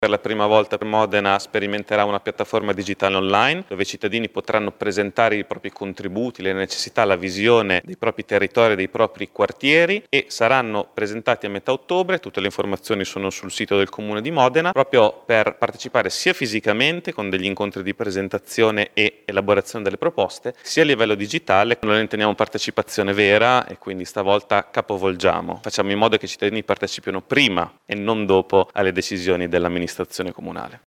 A spiegare il percorso è stato l’assessore alla partecipazione Vittorio Ferraresi…